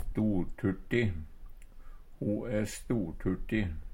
storturti - Numedalsmål (en-US)